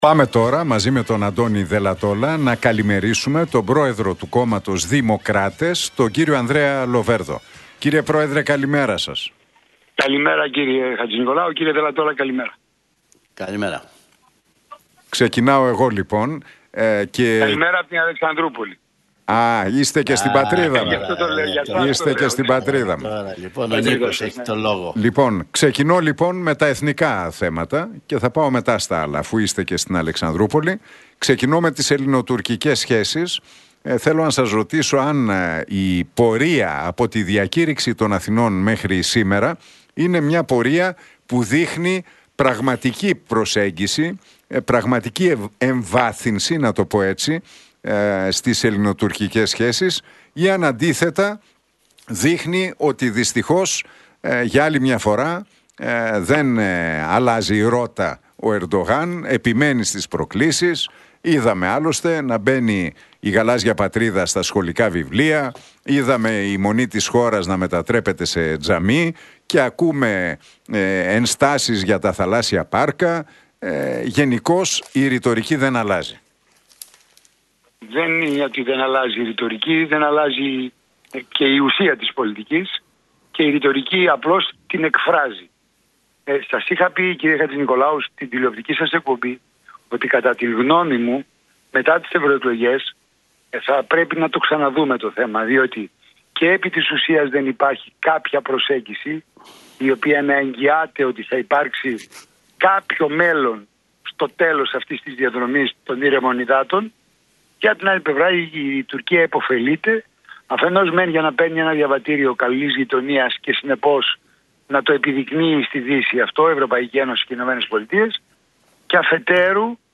Λοβέρδος στον Realfm 97,8: Φαρσοκωμωδία να βλέπω τον Κασσελάκη να απευθύνεται σε φτωχούς και να δείχνει εκατομμύρια να αλλάζουν χέρια